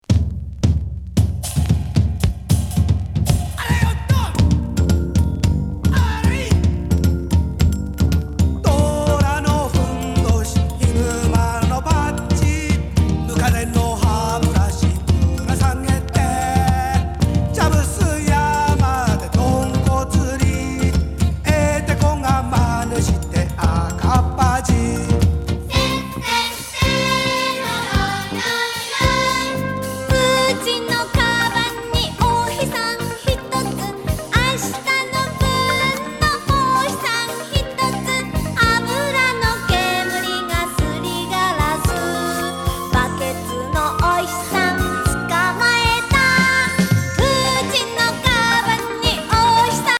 スペーシー・ズンドコ童謡グルーヴ！